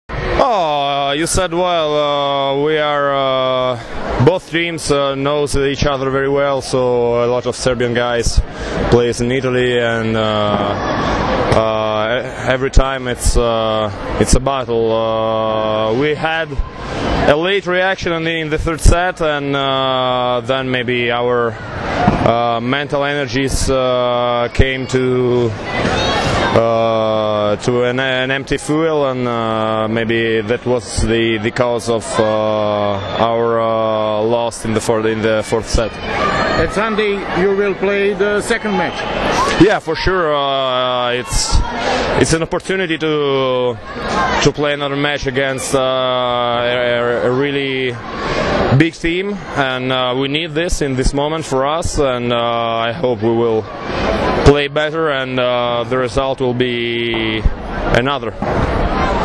IZJAVA IVANA ZAJCEVA